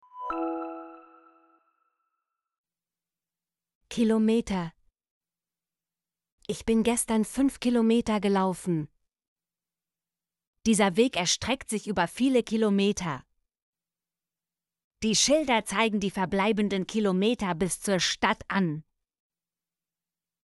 kilometer - Example Sentences & Pronunciation, German Frequency List